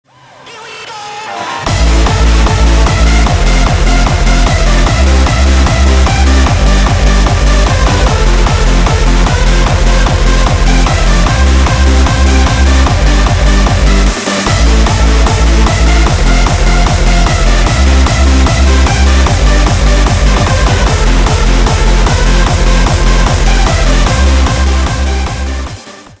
• Качество: 320, Stereo
красивые